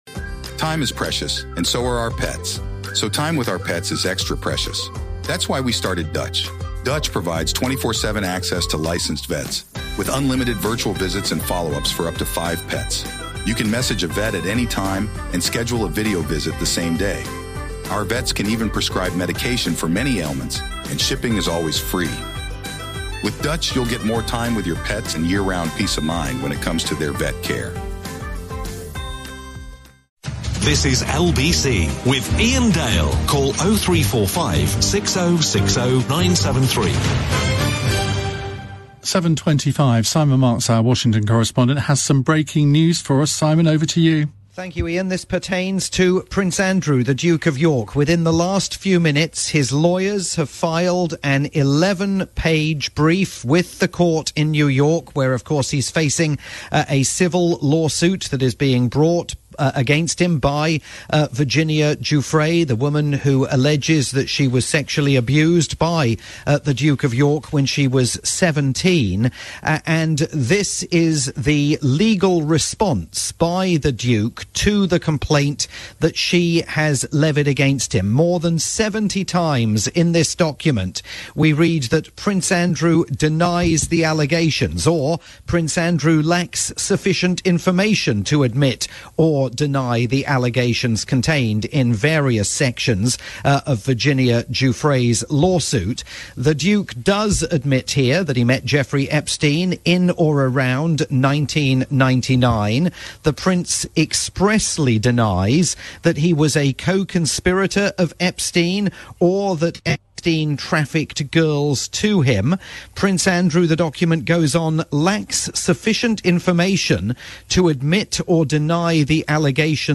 live update on the Duke's latest effort to seek the dismissal of Virginia Giuffre's lawsuit against him.